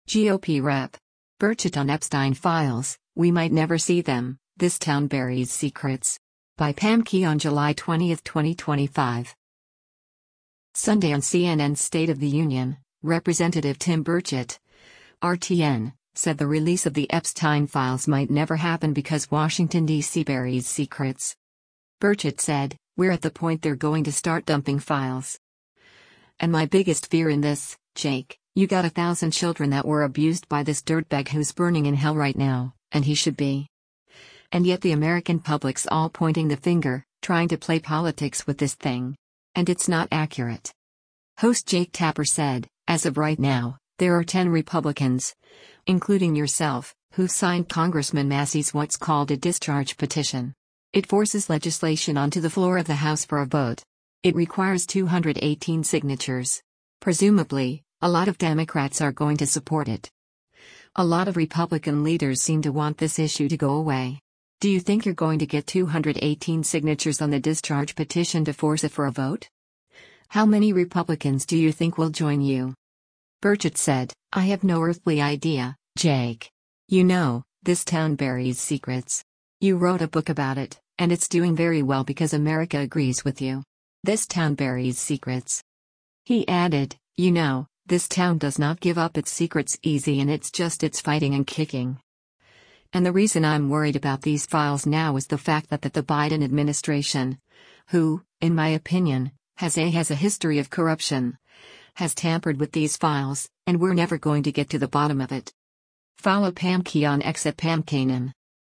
Sunday on CNN’s “State of the Union,” Rep. Tim Burchett (R-TN) said the release of the Epstein files might never happen because Washington, D.C. “buries secrets.”